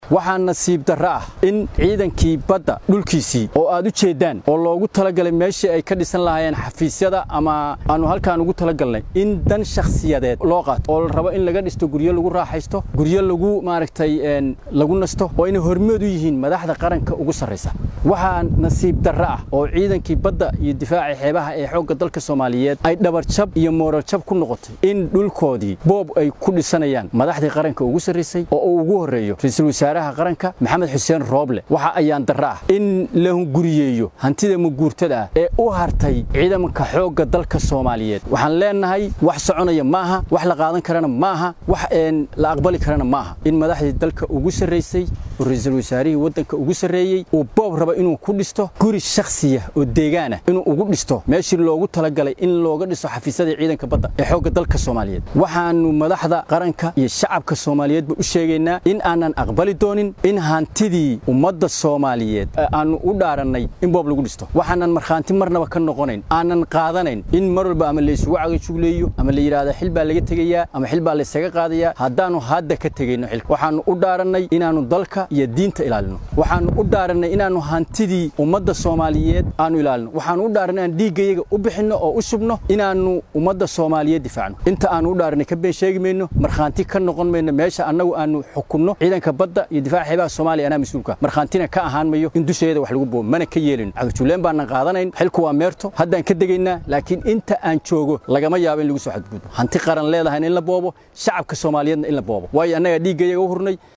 Janeraal Dirir oo warbaahinta kula hadlay goob xeebaha Xamar ka mid ah oo dhisme ka socday ayaa sheegay inaysan aqbalayn in ciidanka dhulkiisa la boobo.